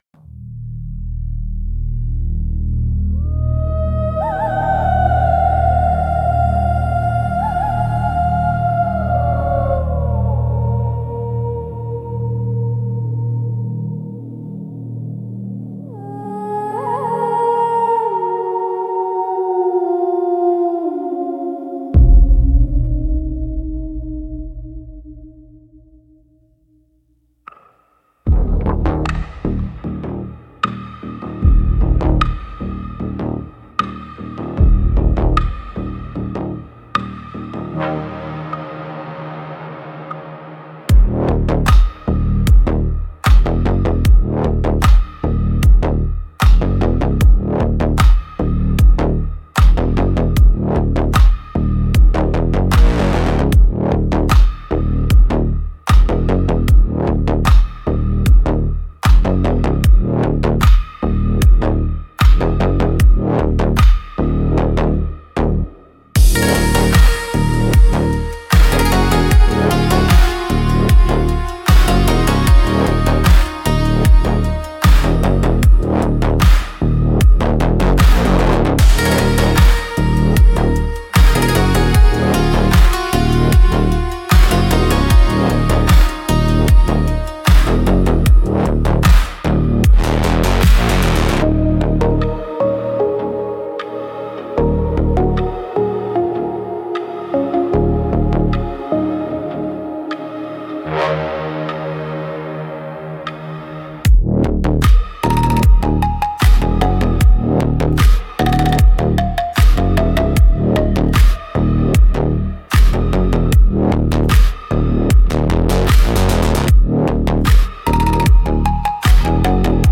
Instrumentals - Elegy for a Drowned Engine